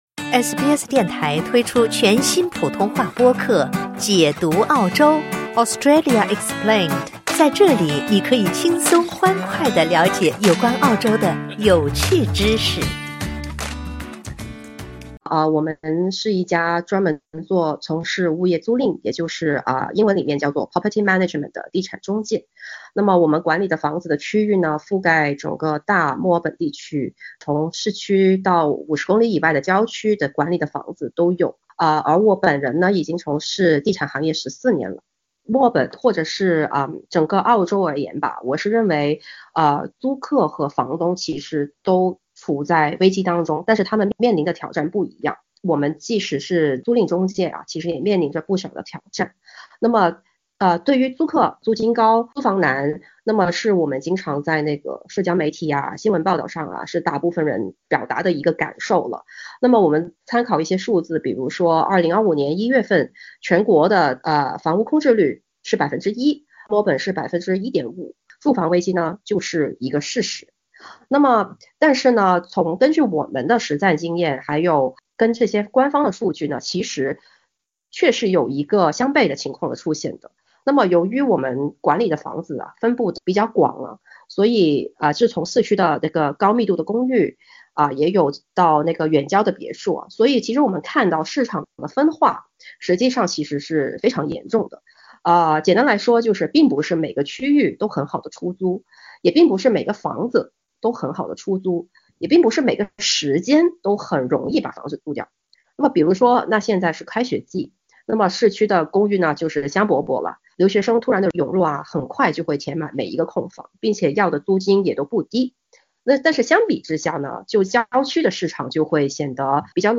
请点击收听完整采访： LISTEN TO 解读：租金危机真的过去了吗？